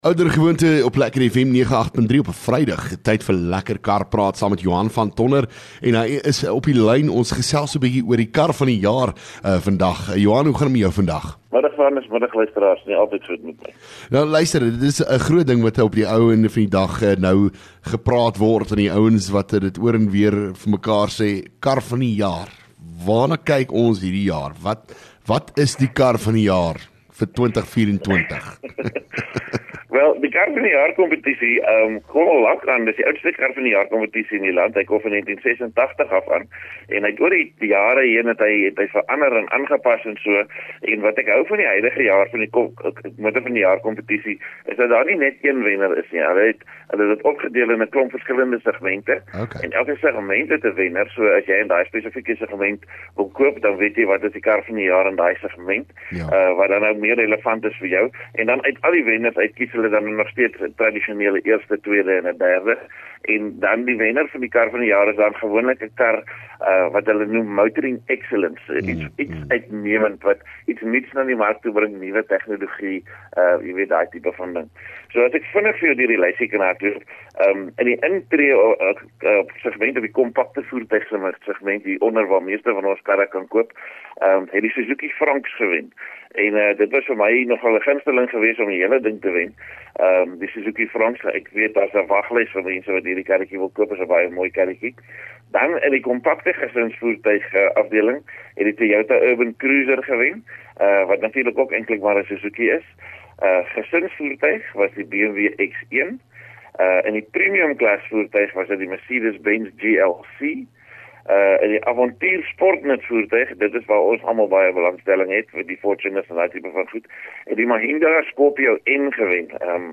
LEKKER FM | Onderhoude 17 May Lekker Kar Praat